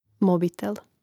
mòbitel mobitel